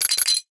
NOTIFICATION_Glass_04_mono.wav